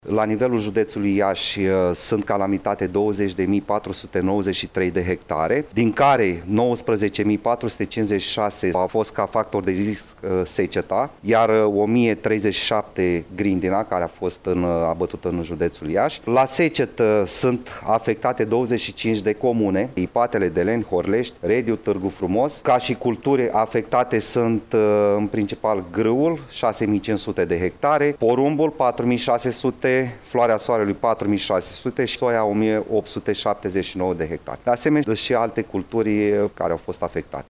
În proporţie de peste 70 la sută sunt afectate localităţile Ciohorani, Grajduri, Răducăneni, Victoria, Grozeşti şi Ţibăneşti, a declarat subprefectul de Iaşi, Bogdan Abălaşei.